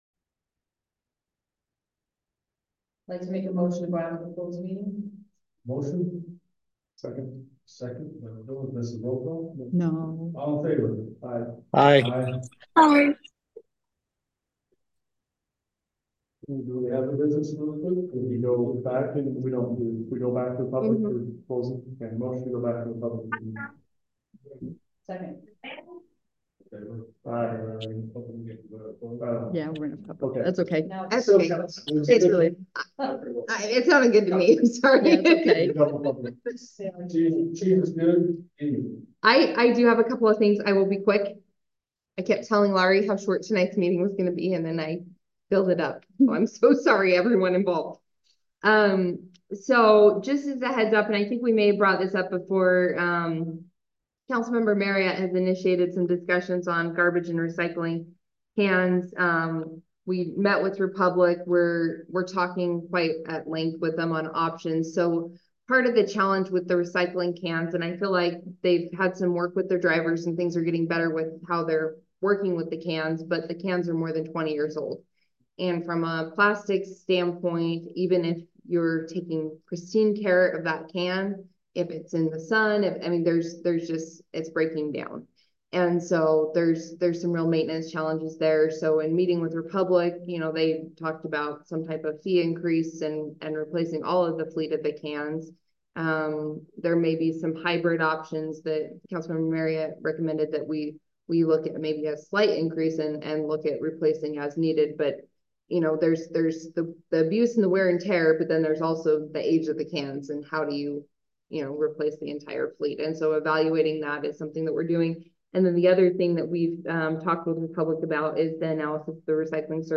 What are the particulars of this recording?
Public Notice is hereby given that the City Council of Pleasant View, Utah will hold a Public Meeting in the city office at 520 West Elberta Dr. in Pleasant View, Utah on Tuesday, February 13, 2024, commencing at 6:00 PM.